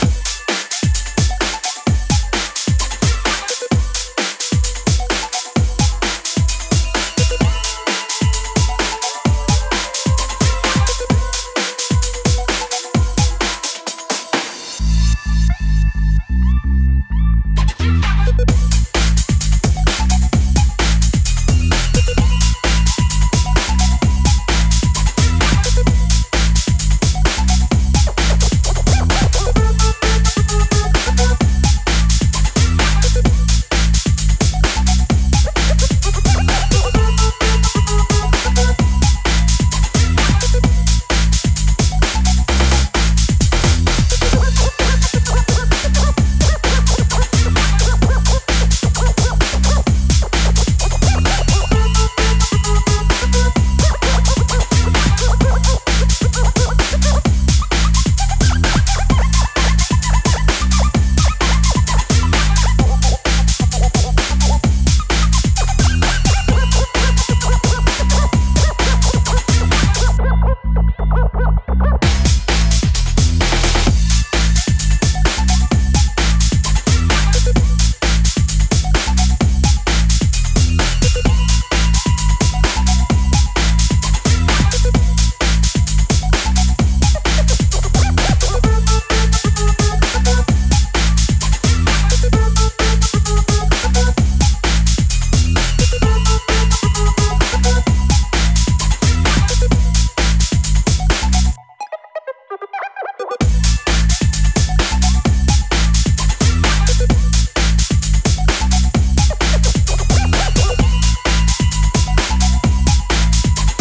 refreshingly modern breakbeat rethink